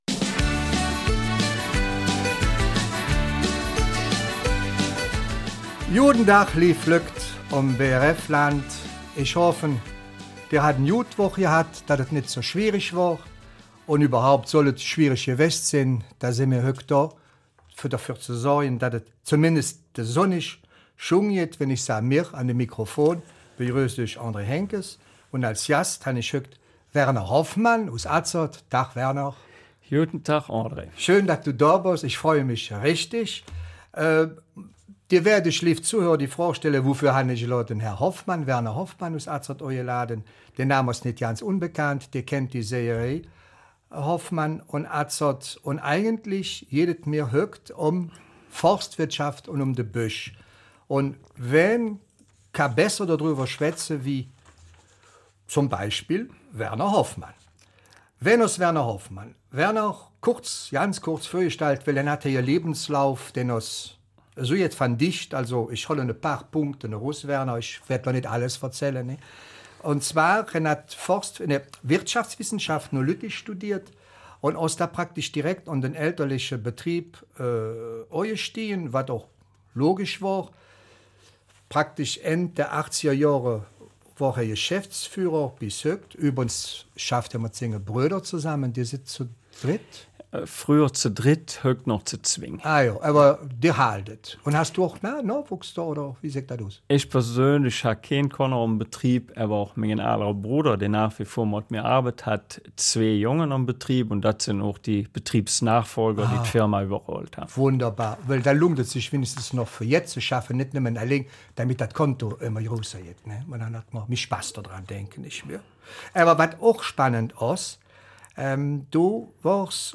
Eifeler Mundart - 11. Mai